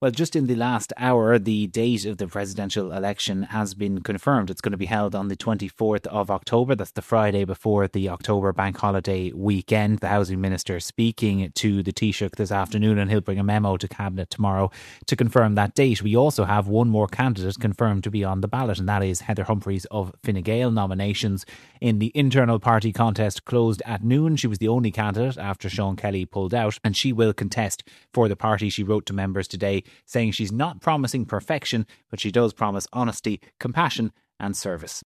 Political Correspondent